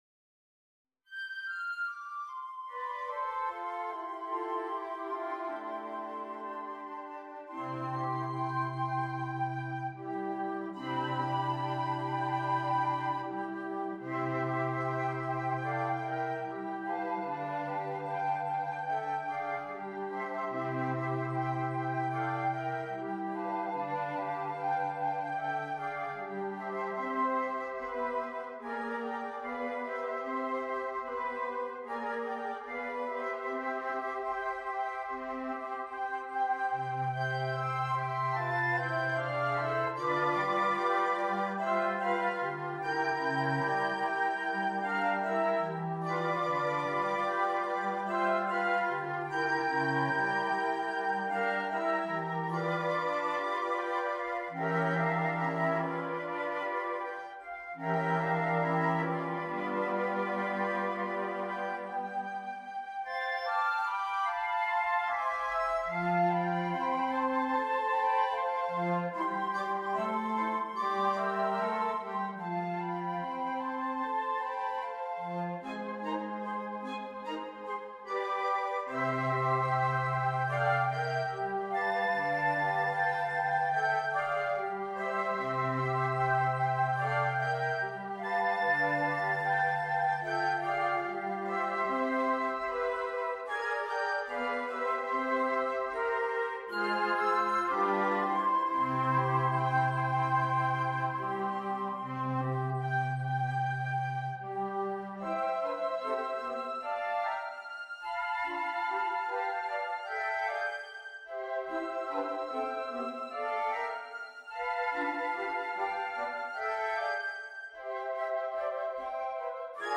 woodwind ensemble
It is a very accessible blues piece
[Picc/4 Fl/Fl or Alto Fl/Alto Fl/Bs Fl] Sample score https